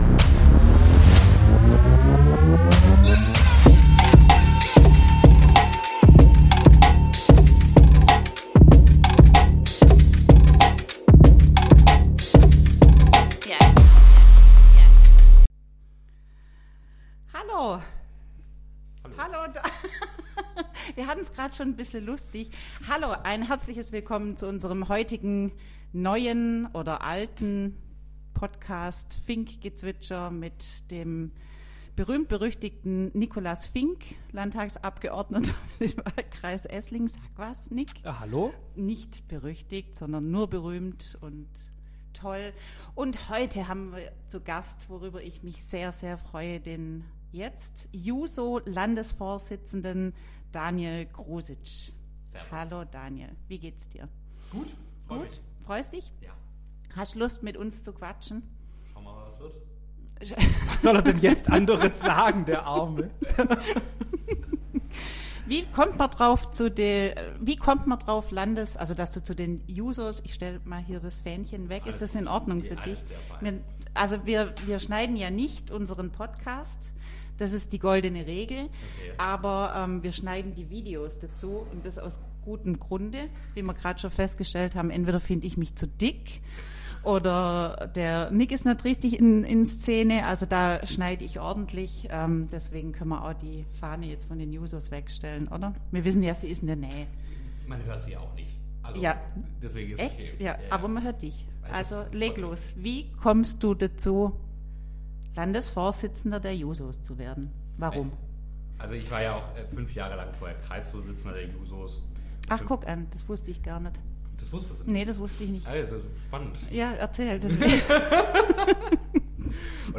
Das Gespräch “Jung vs. Alt” ist nicht nur ein interessanter Austausch, sondern auch ein Appell an die Politik, gemeinsam zu handeln und alle Generationen mit einzubeziehen.